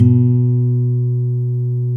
Index of /90_sSampleCDs/Roland L-CDX-01/GTR_Nylon String/GTR_Nylon Chorus